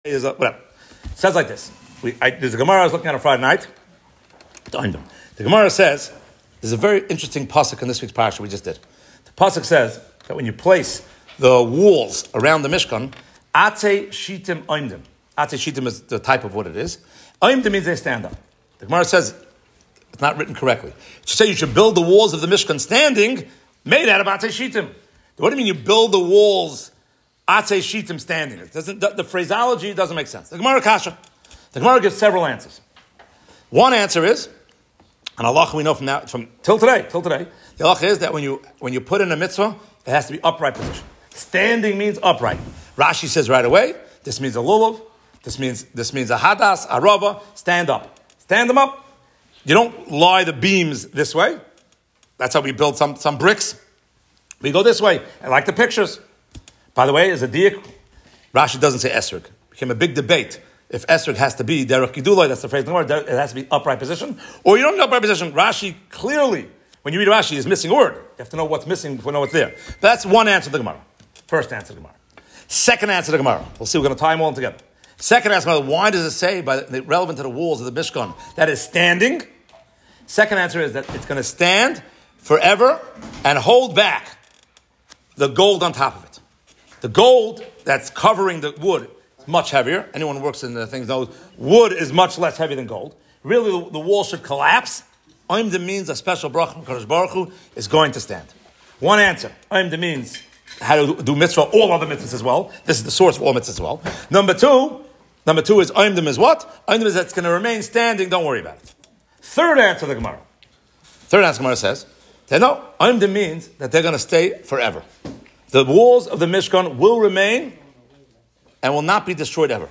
Gemara Suka (45b) offers 3 explanations (maybe they are one). From Heimish shul of Houston, TX.